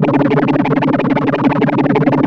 3100 FFA C#4.wav